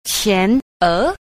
2. 前額 – qián é – tiền ngạch (trán)